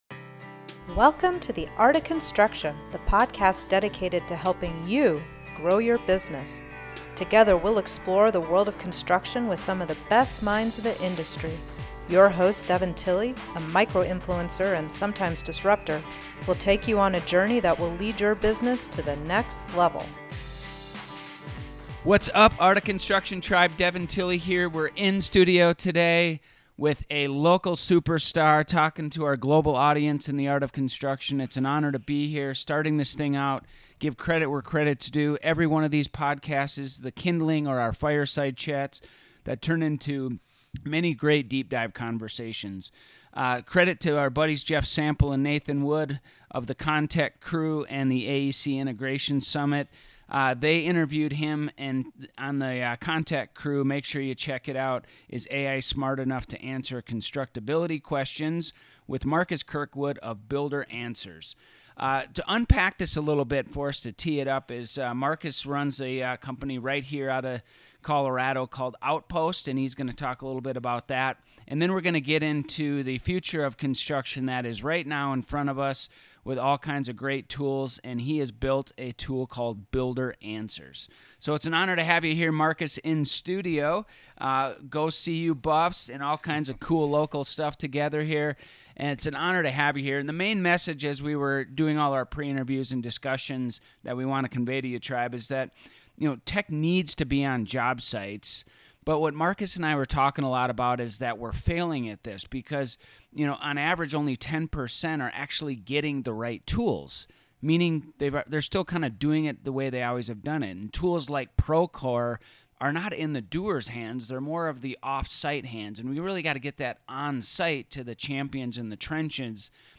This podcast was recorded LIVE in studio at Mountain View Windows and Doors ! They discuss the need for technology in the construction industry and how Builder Answers is addressing that need. They also talk about the importance of adopting new tools and technologies in construction and the challenges of implementing them.